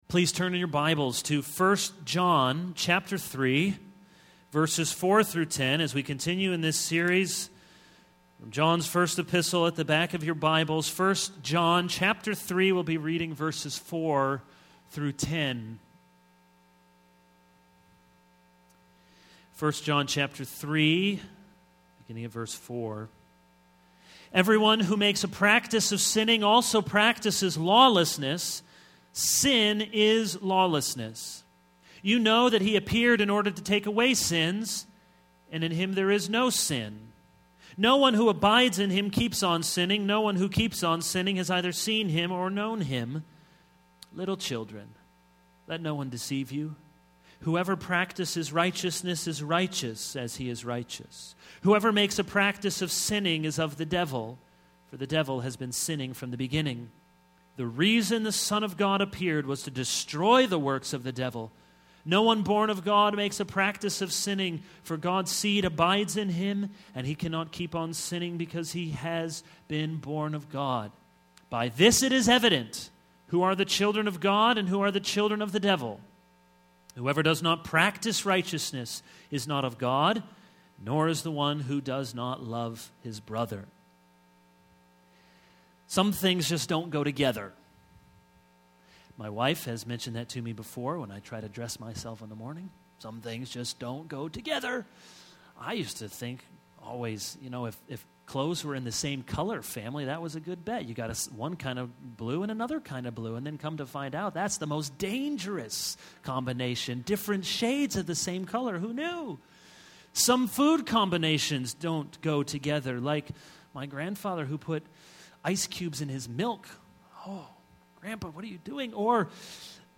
This is a sermon on 1 John 3:4-10.